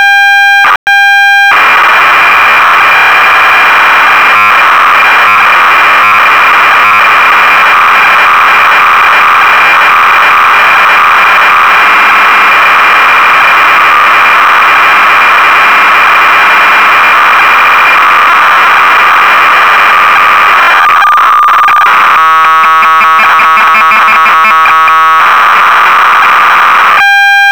Color BASIC casette audio WAV